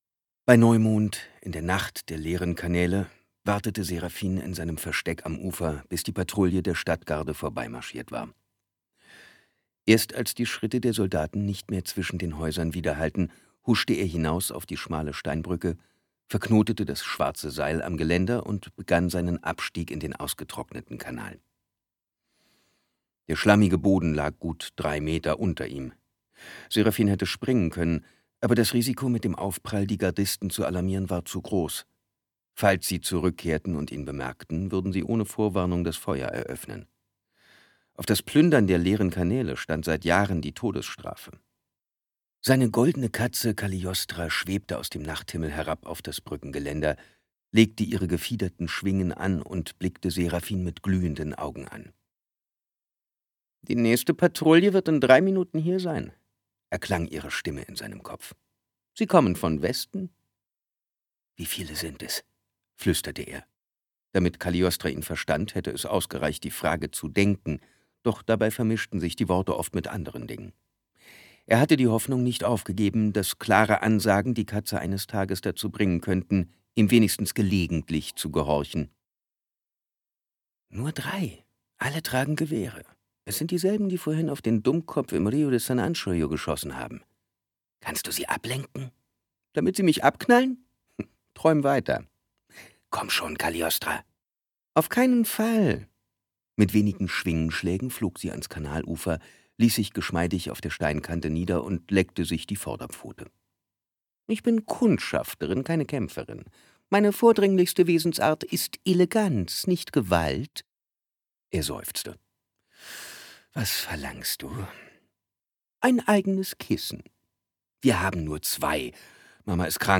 Serafin. Das Kalte Feuer Merle-Zyklus 4 Kai Meyer (Autor) Simon Jäger (Sprecher) Audio Disc 2020 | 2.